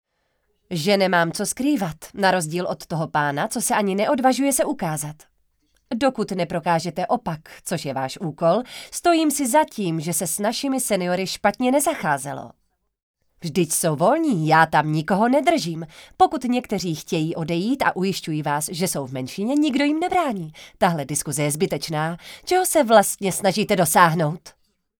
Ukázka slovenština:
Dabing: